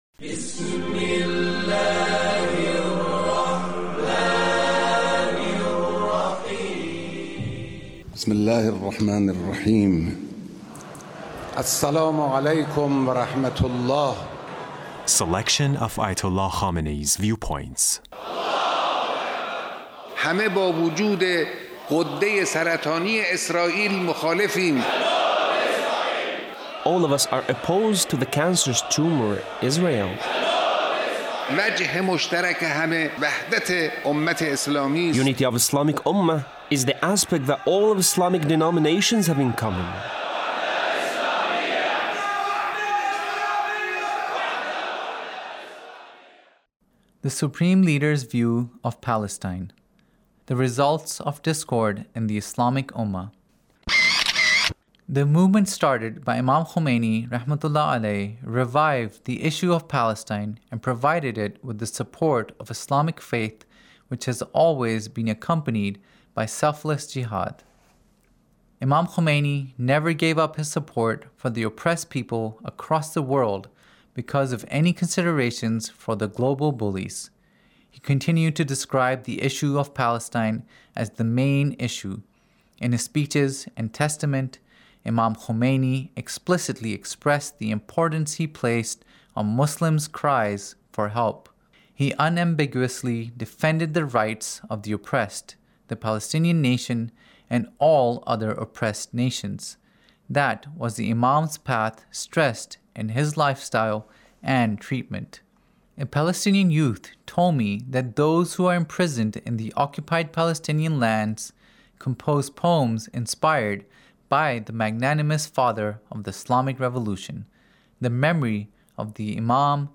Leader's Speech (1866)
Leader's Speech on Palestine